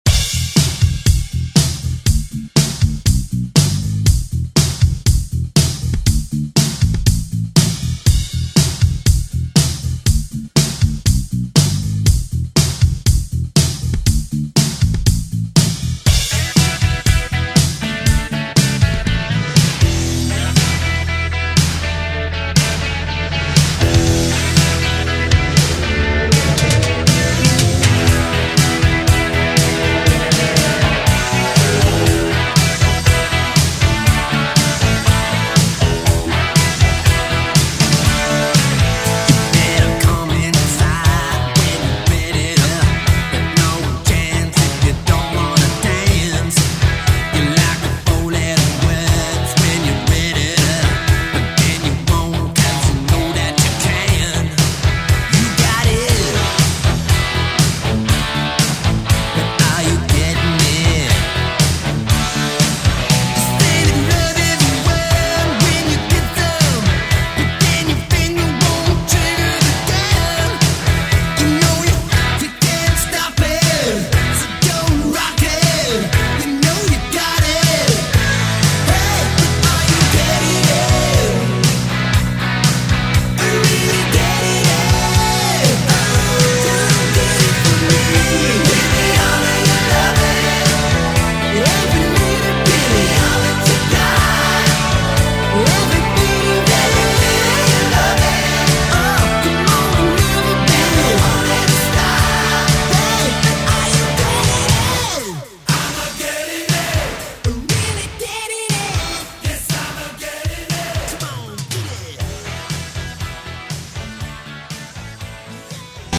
Hip Hop RnB Funk Soul
Extended Intro Outro
96 bpm